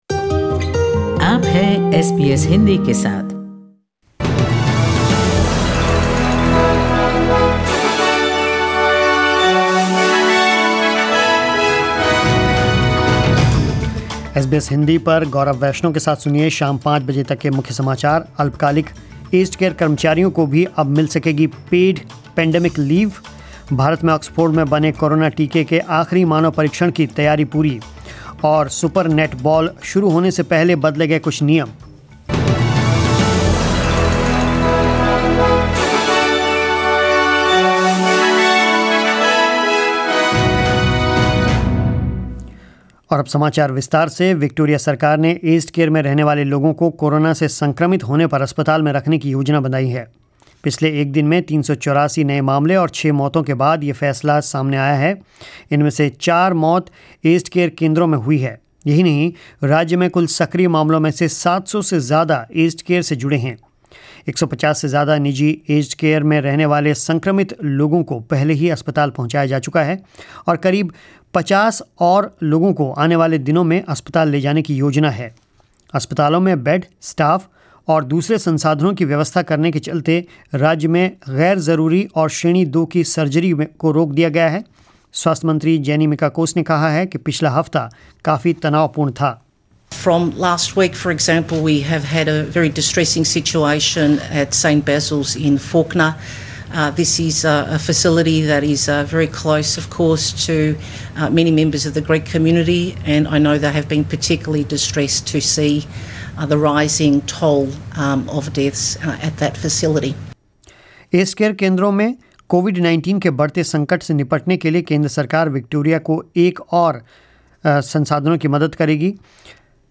News in Hindi 28 July 2020